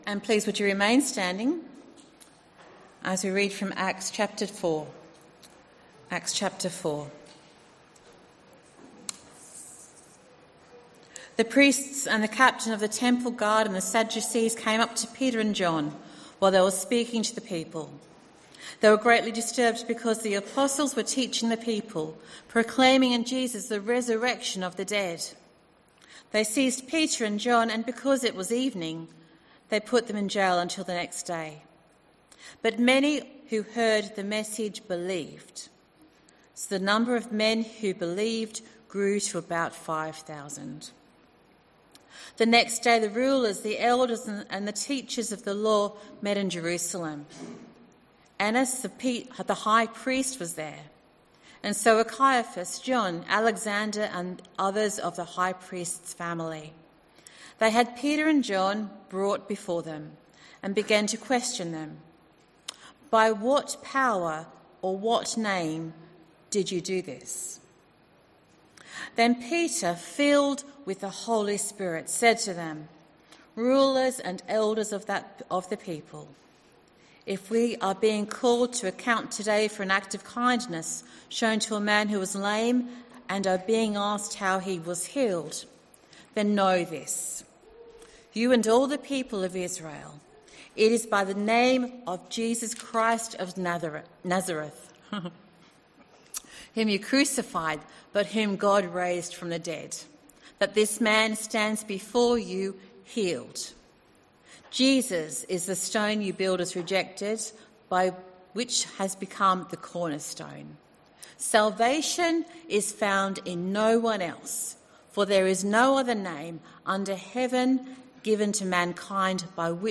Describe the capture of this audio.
Bible reading and sermon from 10AM meeting at Newcastle Worship & Community Centre of The Salvation Army. The Bible reading is taken from Acts 4:5-12.